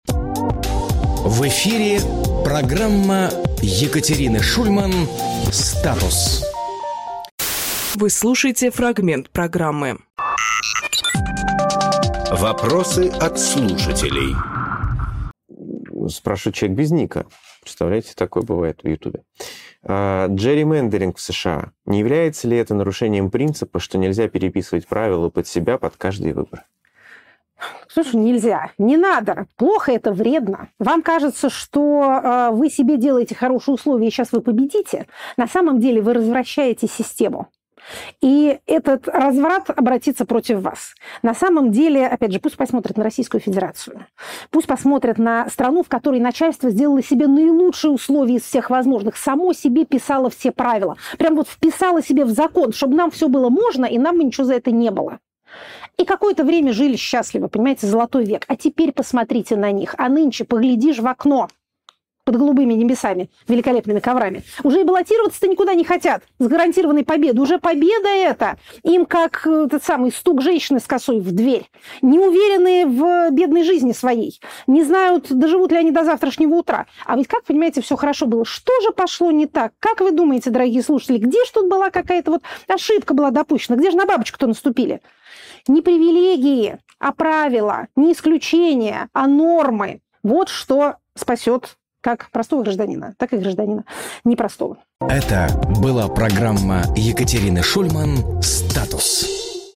Екатерина Шульманполитолог
Фрагмент эфира от 26.08.25